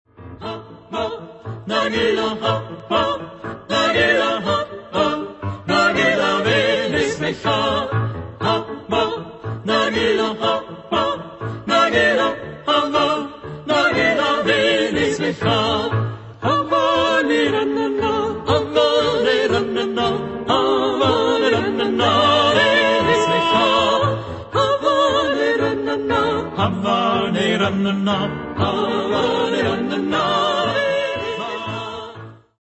Genre-Style-Form: Secular ; Traditional
Mood of the piece: exalted
Type of Choir: SATB  (4 mixed voices )
Instrumentation: Piano  (1 instrumental part(s))
Tonality: F major